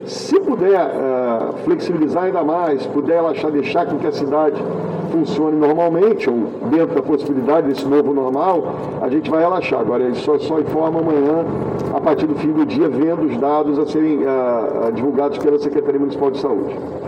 Declaração foi dada durante inauguração de posto de vacinação na Base Aérea do Galeão